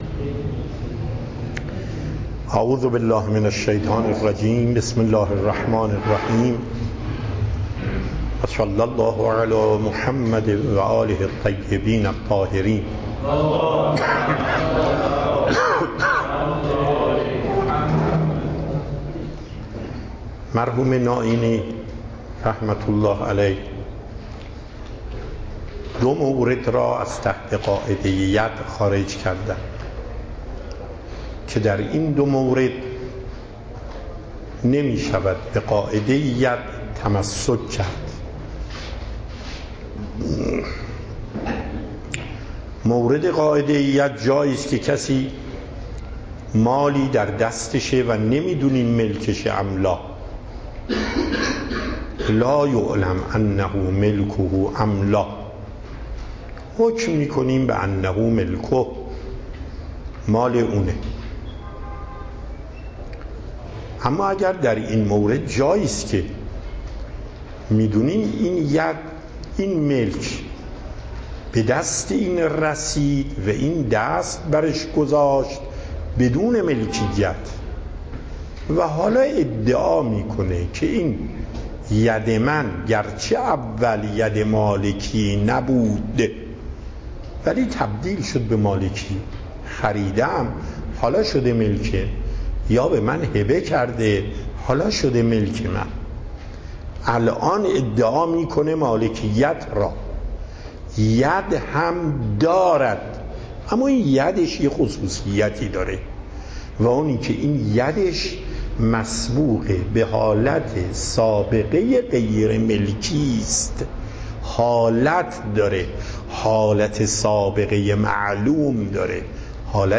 درس اصول آیت الله محقق داماد